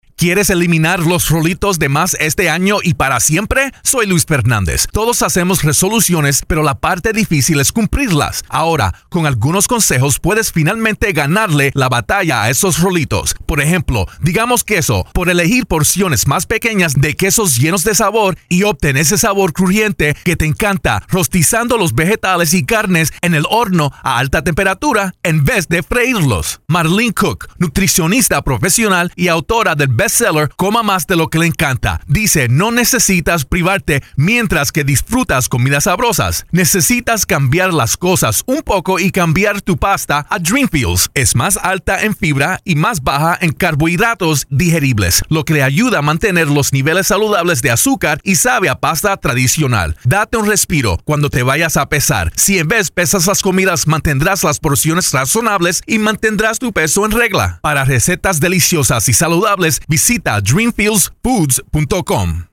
January 30, 2013Posted in: Audio News Release